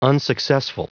Prononciation du mot unsuccessful en anglais (fichier audio)
Prononciation du mot : unsuccessful